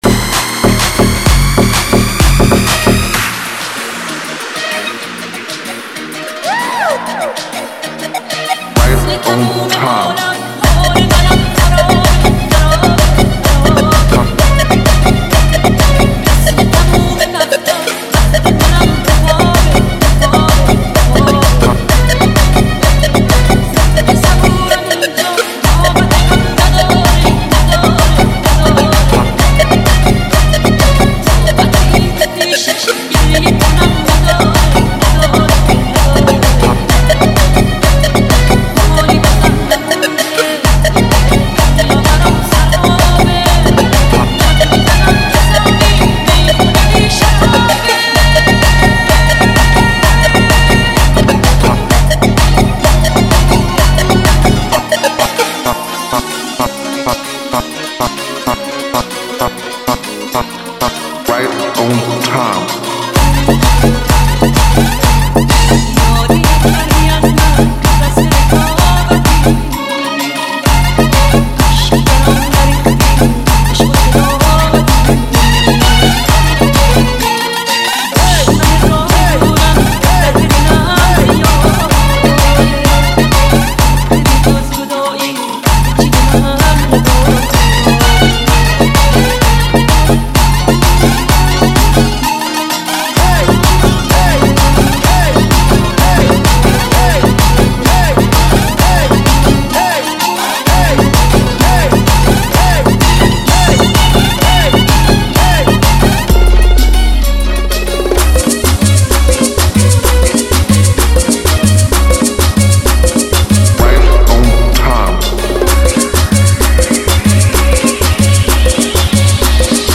ریمیکس تریبال شاد مخصوص پارتی و رقص